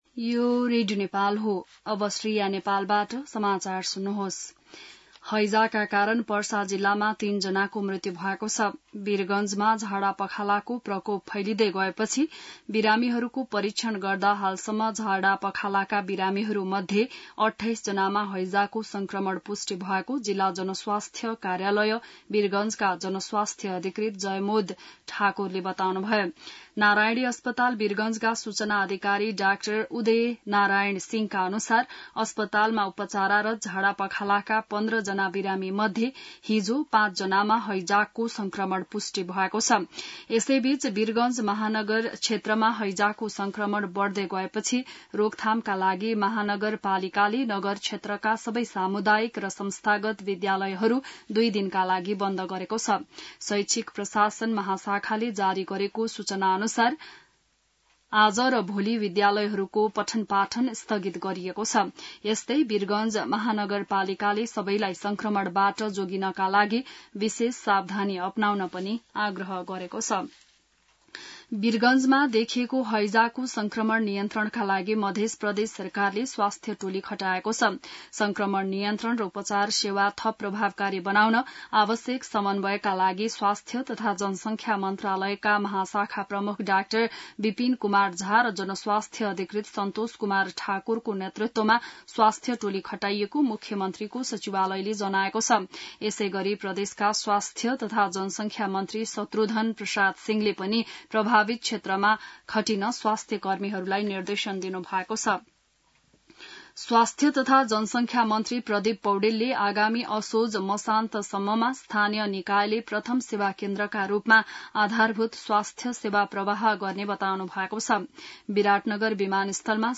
बिहान ६ बजेको नेपाली समाचार : ८ भदौ , २०८२